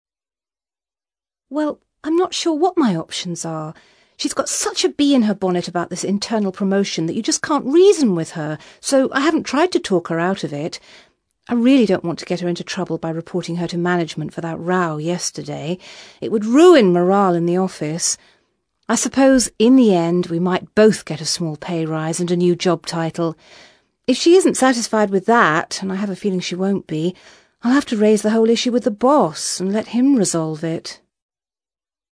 ACTIVITY 162: You will hear five short extracts in which five people are talking about problems related to their work.